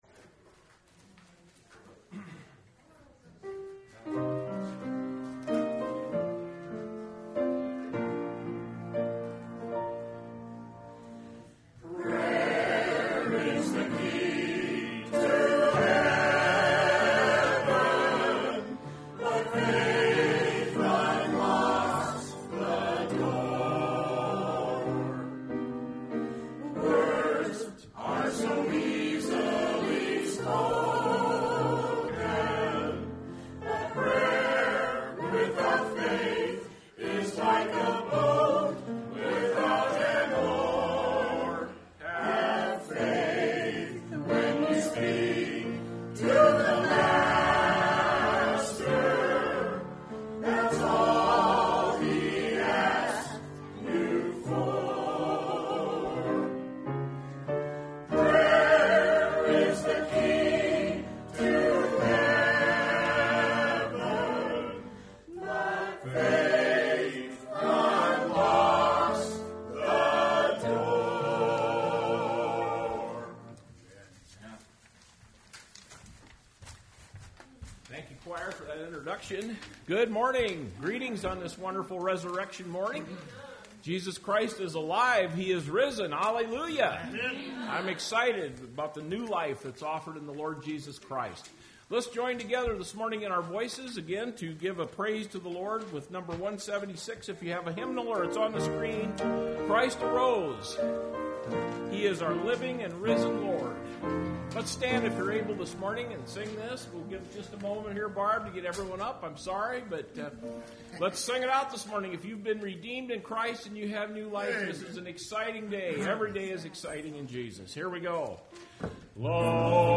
Service Type: Sunday Morning Service Topics: Easter , Resurrection Day , Salvation « A Survey from the Triumphal Entry to the Trials